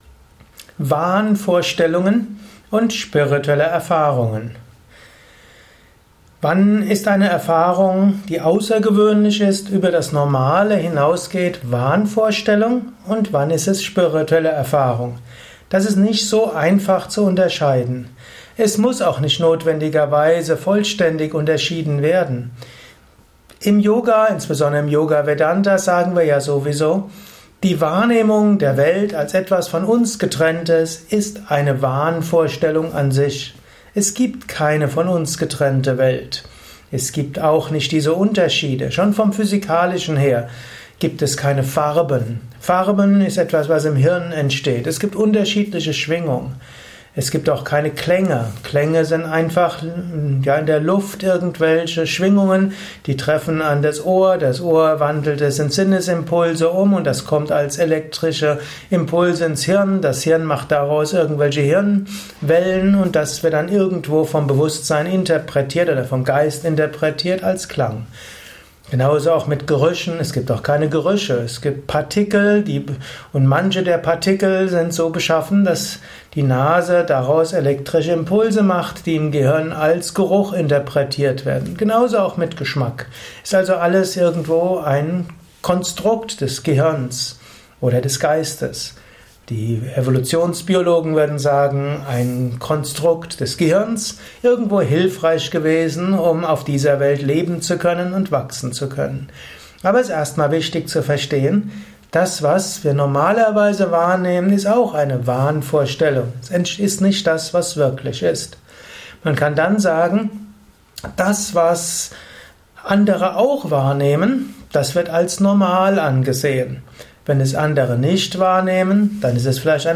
Inspirierender Abhandlung mit dem Gegenstand Wahnvorstellungen und spirituelle Erfahrungen. Simples und Komplexes zum Thema Wahnvorstellungen in diesem Kurzvortrag.